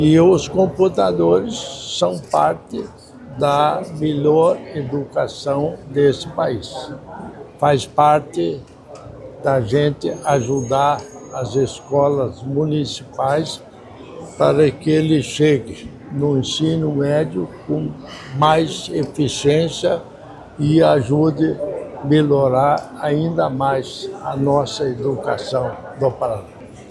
Sonora do governador em exercício Darci Piana sobre o anúncio de R$ 63,5 milhões em kits tecnológicos para escolas municipais